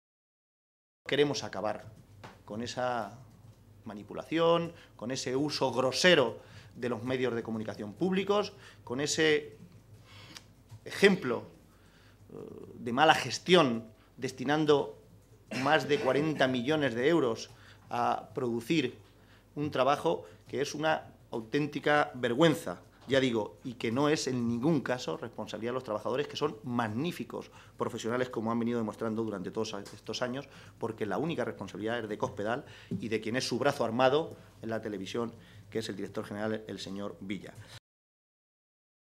Caballero se pronunciaba de esta manera esta mañana, en Toledo, en una comparecencia ante los medios de comunicación en la que explicaba que esta iniciativa tiene como objetivo “acabar con la manipulación y la tergiversación vergonzosa que sufren la televisión de Castilla-La Mancha desde que está en manos de Cospedal y Villa y que solo es equiparable a la de la televisión de Corea del Norte”.